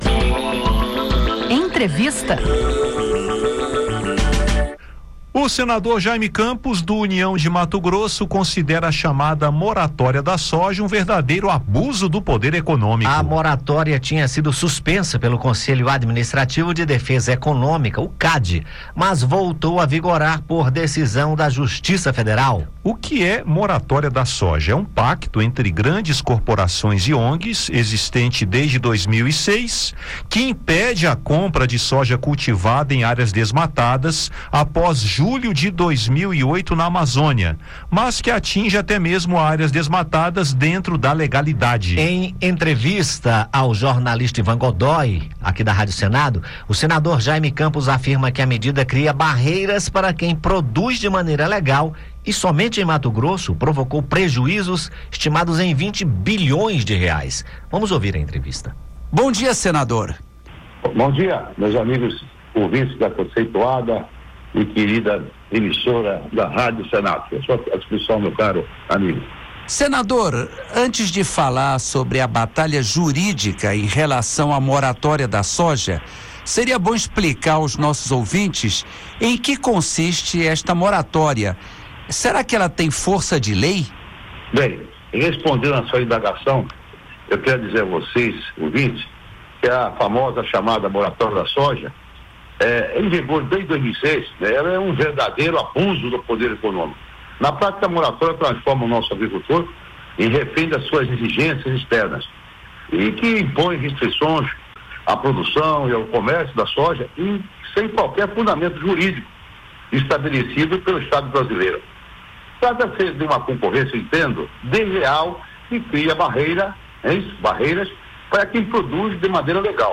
O senador Jayme Campos (União-MT), considera a moratória da soja um abuso do poder econômico e afirma que a medida cria barreiras para quem produz de maneira legal e, somente em Mato Grosso, provocou prejuízos estimados em 20 bilhões de reais. Acompanhe a entrevista.